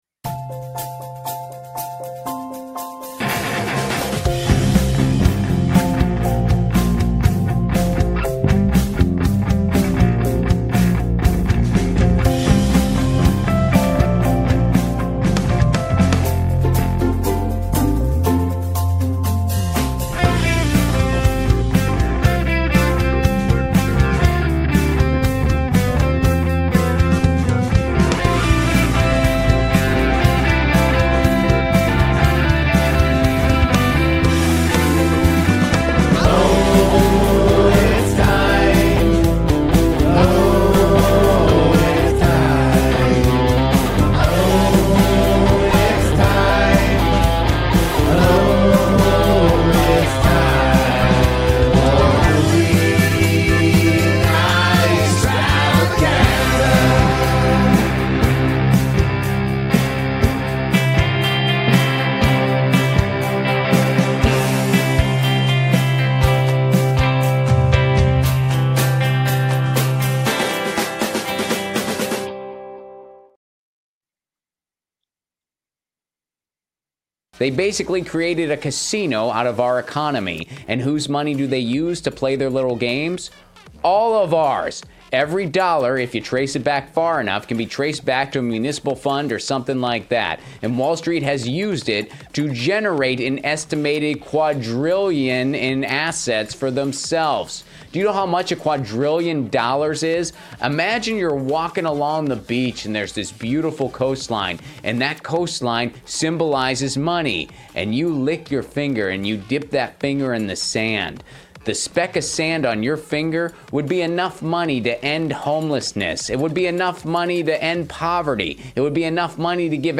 Movie Night Extravaganza is an irreverent, quick moving show about movies.